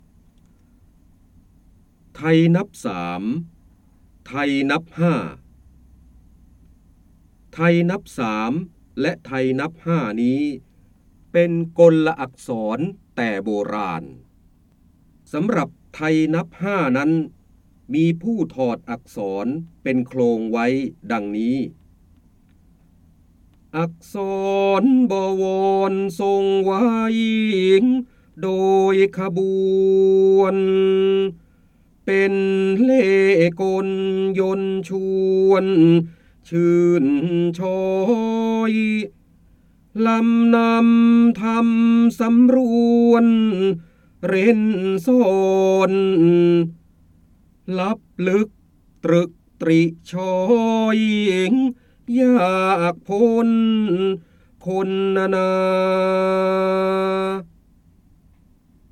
เสียงบรรยายจากหนังสือ จินดามณี (พระโหราธิบดี) ไทยนับ๓ ไทยนับ๕
คำสำคัญ : จินดามณี, พระเจ้าบรมโกศ, พระโหราธิบดี, ร้อยแก้ว, การอ่านออกเสียง, ร้อยกรอง